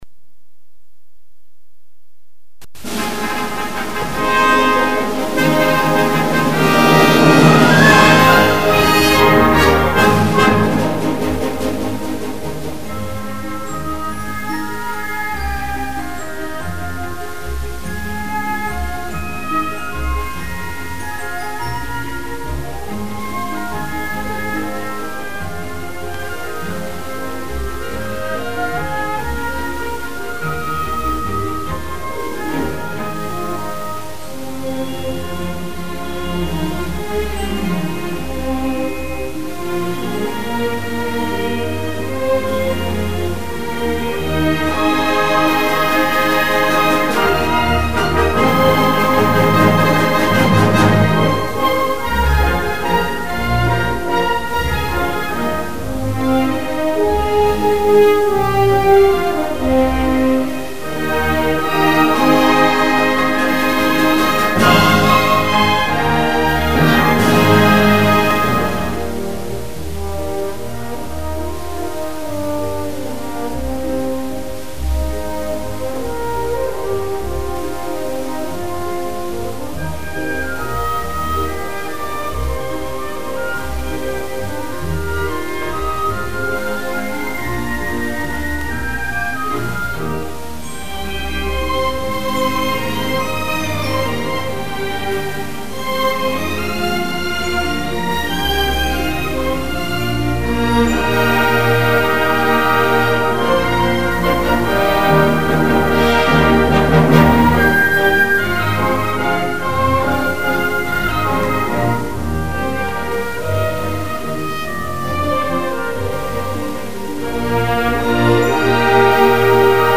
[12/1/2014]管弦乐 我爱祖国的蓝天